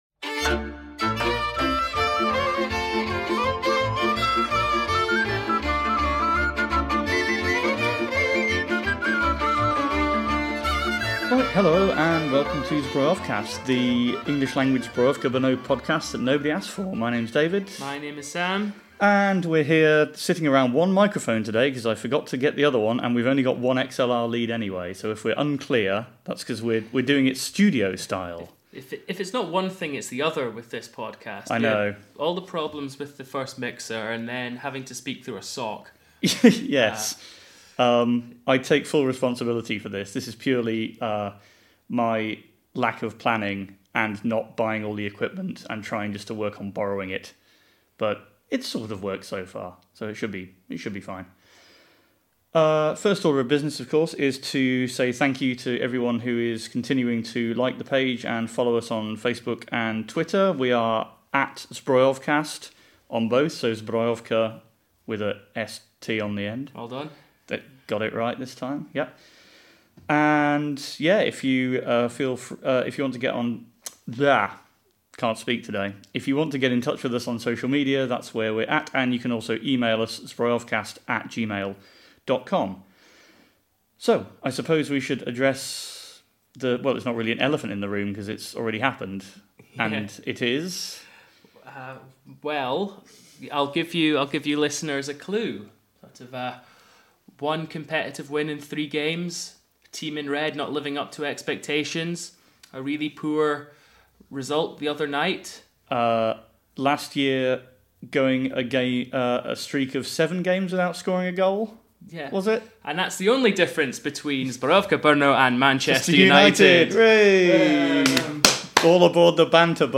Featuring managerial sacking, a shocked reaction to a bottle of Kanec, a Simpsons singalong and an actual victory in an actual game of football.